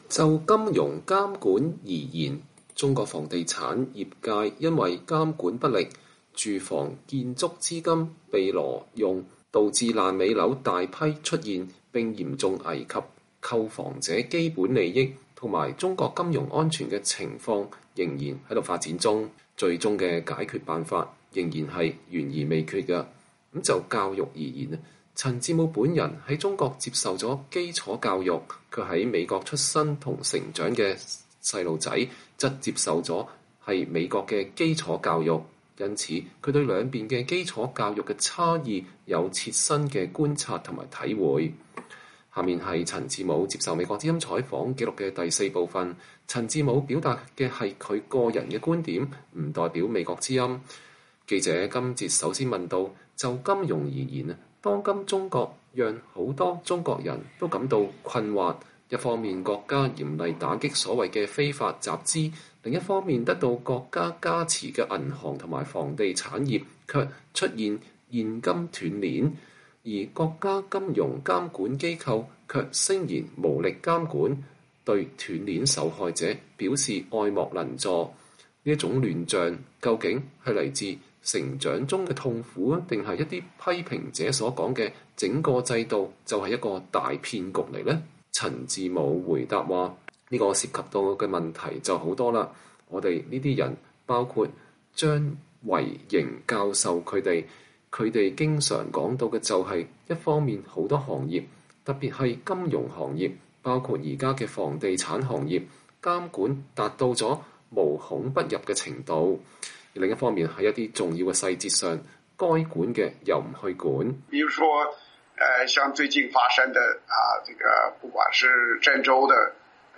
專訪陳志武（4）：談中國金融監管與教育